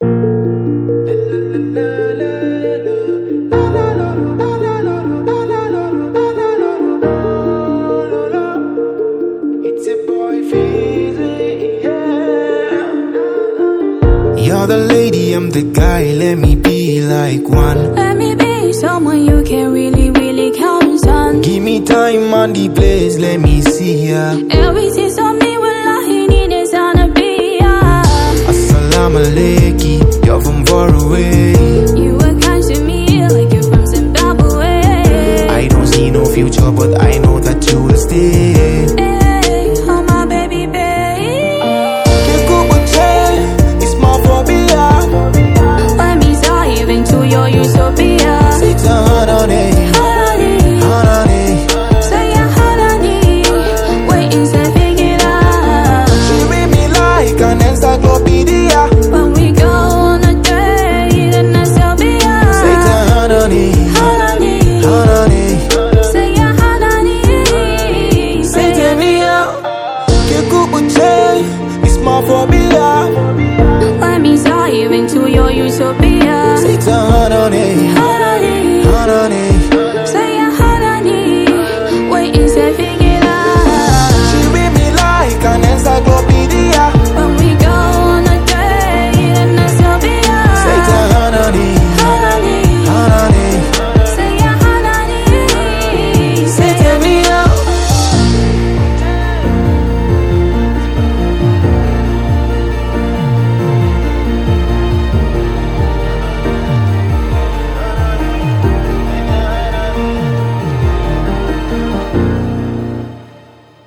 Hausa Hip Hop